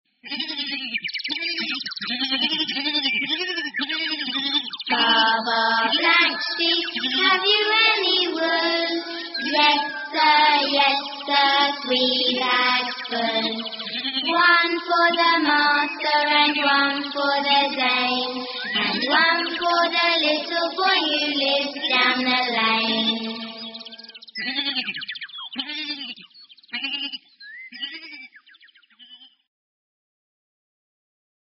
鹅妈妈童谣 21 Baa, Baa, Black sheep 听力文件下载—在线英语听力室